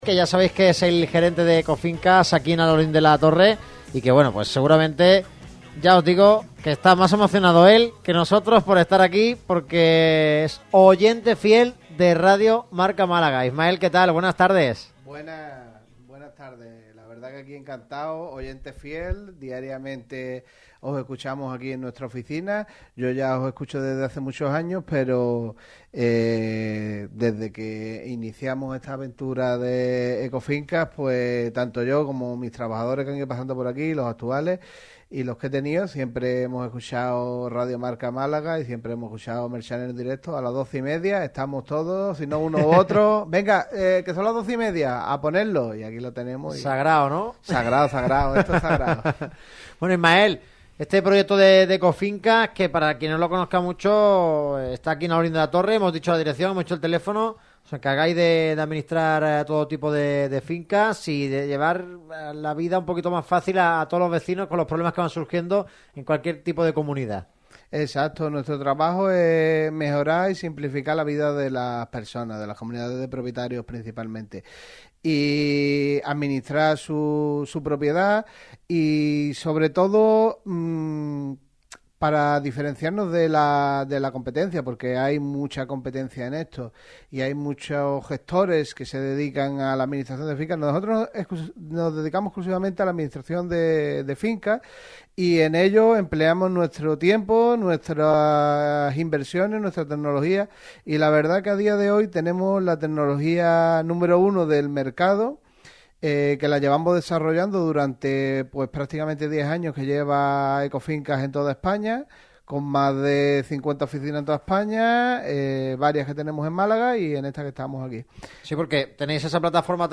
El equipo de Radio Marca Málaga se ha trasladado hoy hasta Ecofincas, junto al Parque de San Juan Pablo II, en Alhaurín de la Torre. Un programa muy especial, ya que es el primer ‘Merchán en Directo’ que se realiza en este local.